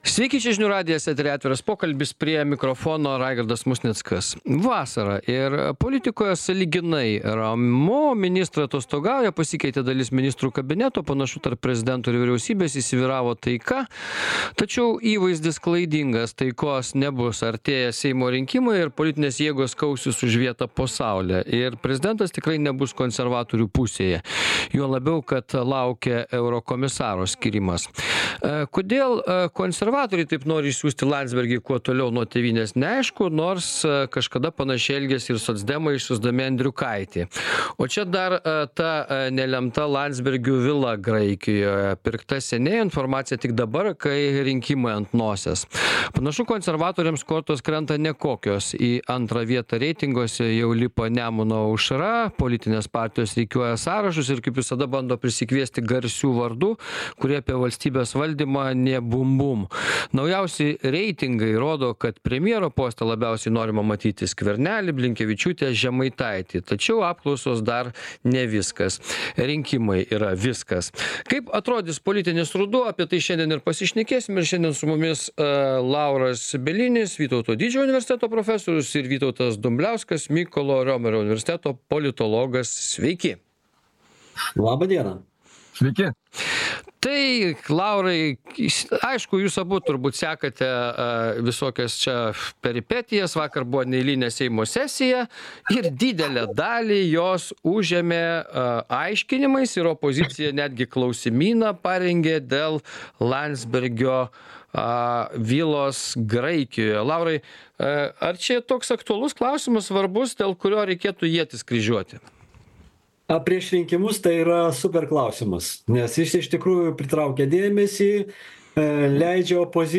Atviras pokalbis Žiūrėkite Atsisiųsti Atsisiųsti Kaip atrodys politinis ruduo? 2024-08-14